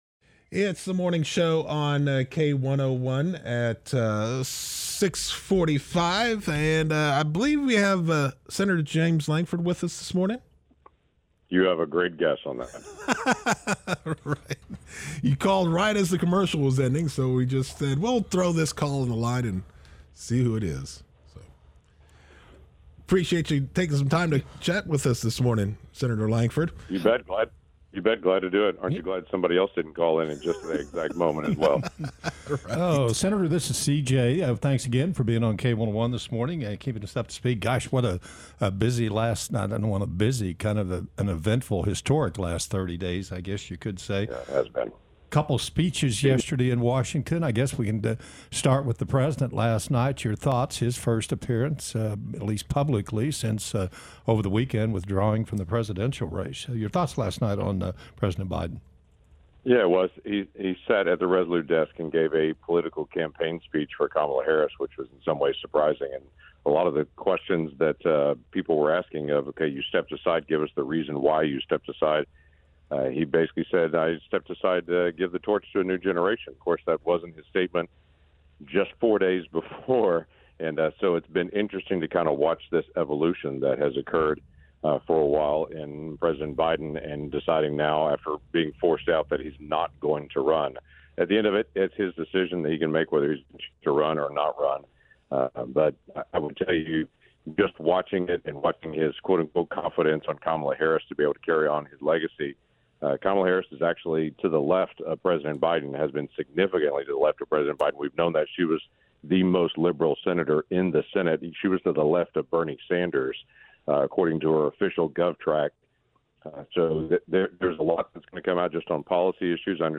ICYMI: Sen. Lankford on K-101 Morning Show